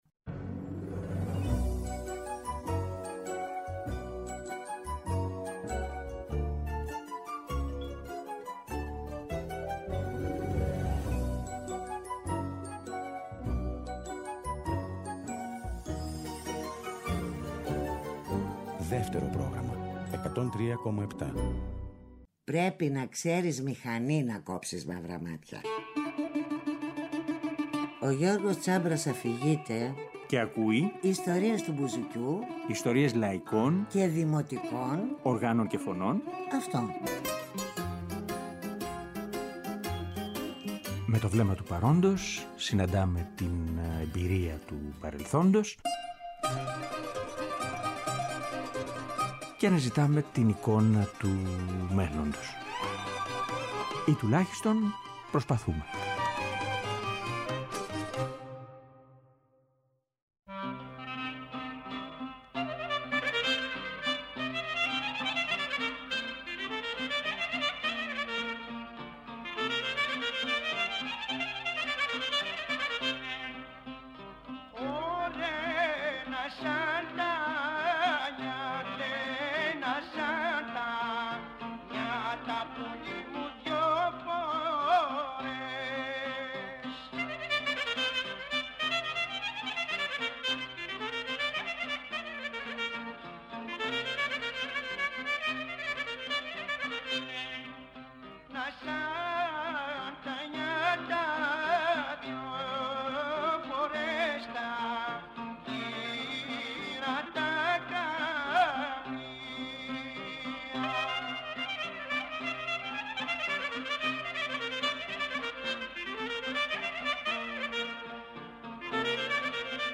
Τώρα που το σκέφτομαι ίσως είναι που η φωνή του κουβαλάει την τραχύτητα αλλά και την ευαισθησία των βουνών.